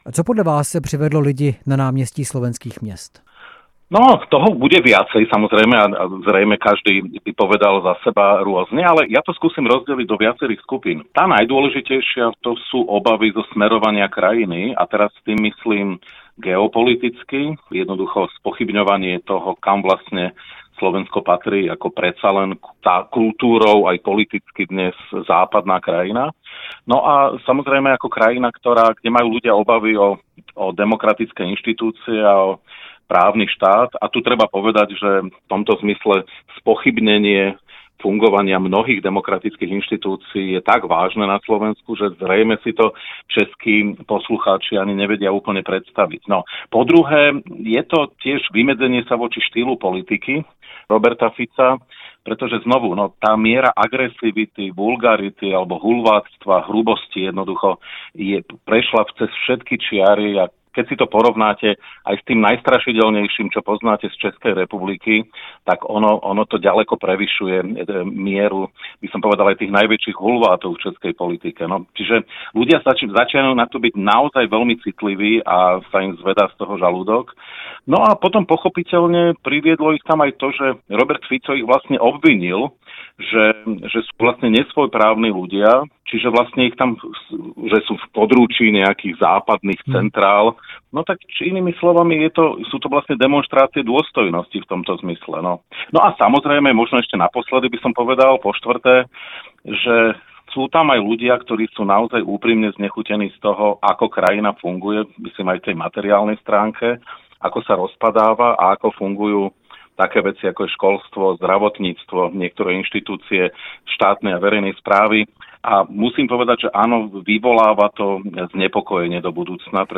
Živé vysílání